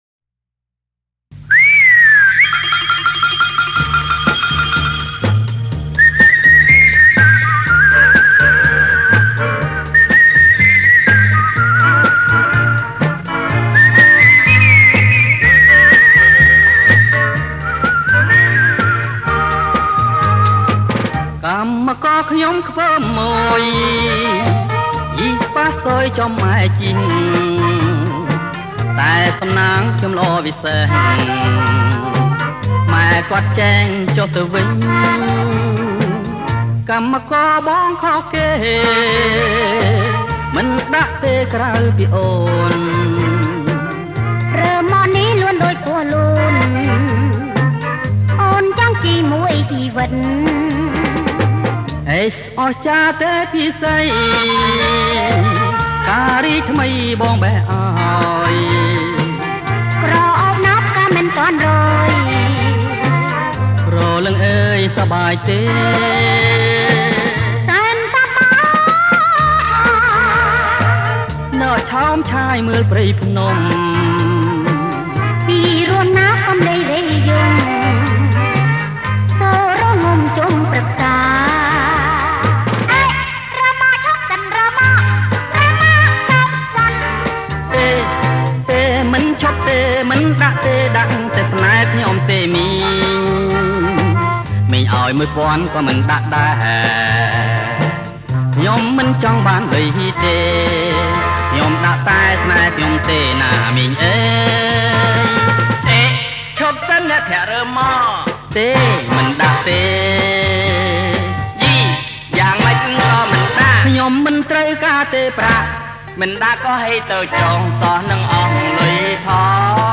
ប្រគំជាចង្វាក់ Jerk Lent